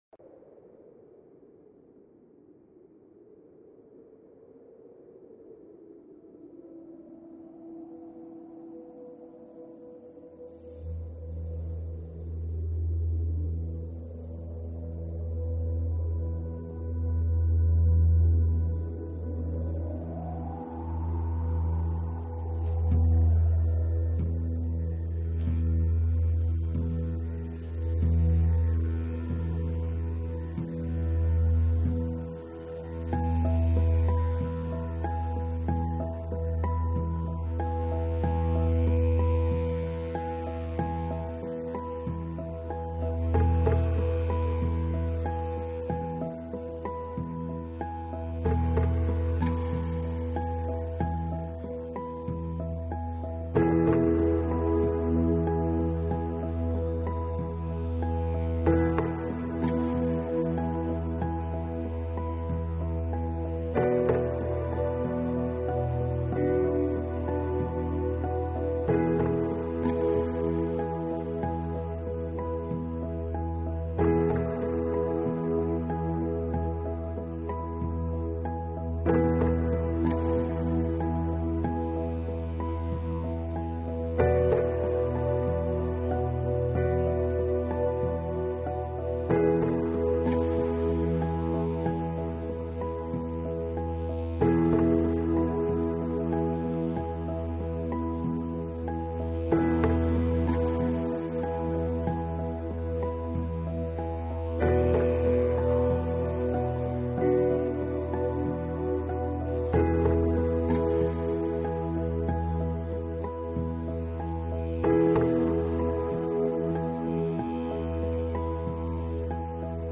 Genre..........: New Age
These peaceful tracks